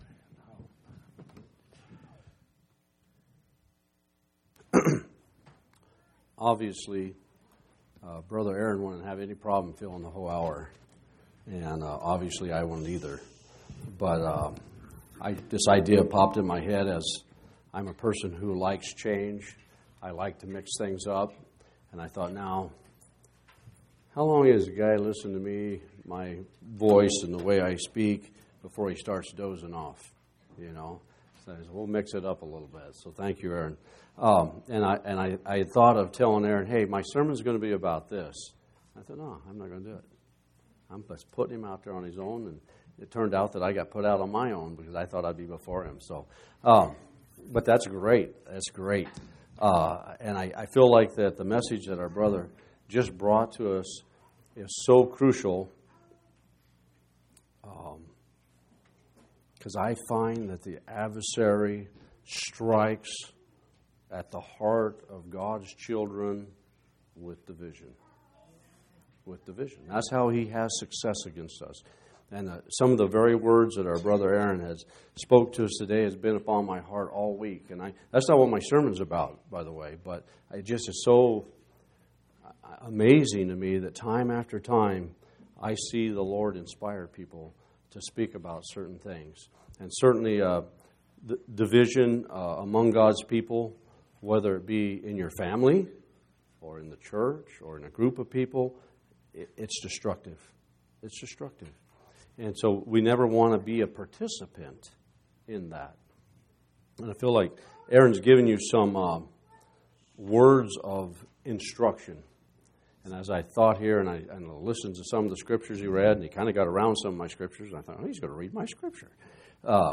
7/31/2016 Location: Phoenix Local Event